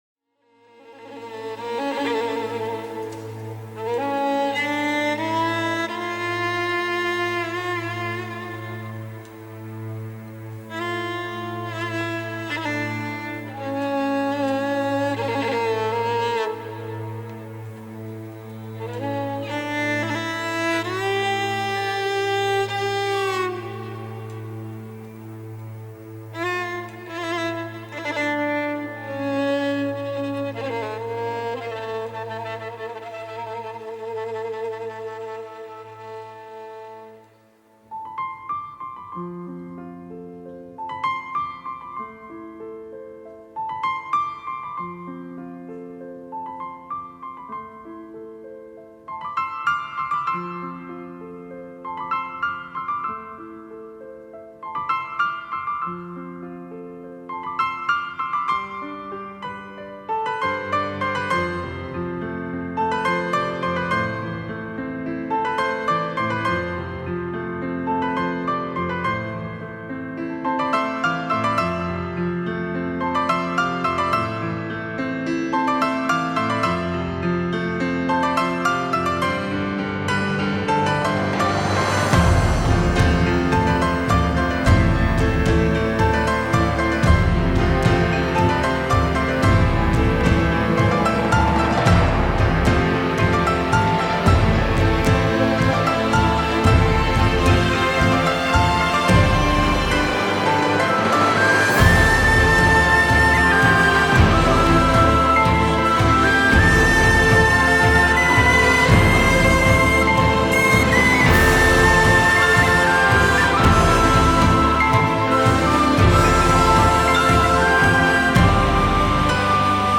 Die nachfolgende Melodie kann Dir bei der Innenreise helfen.